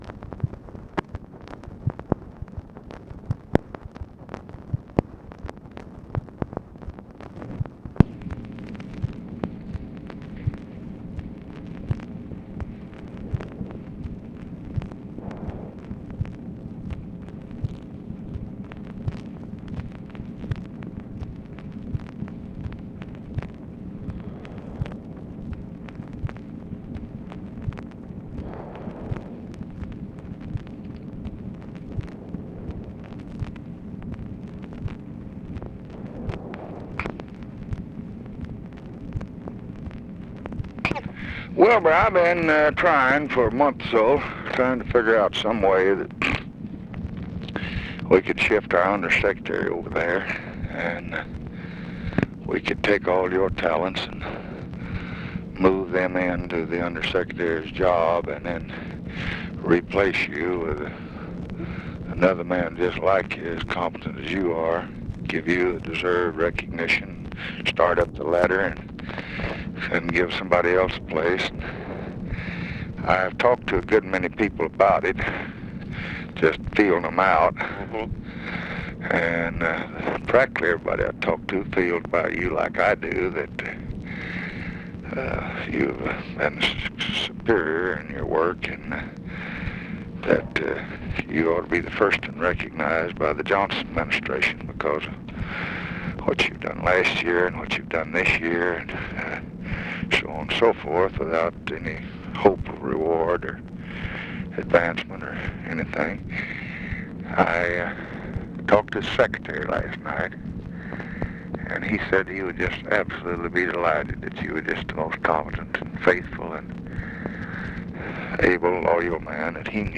Conversation with WILBUR COHEN, April 1, 1965
Secret White House Tapes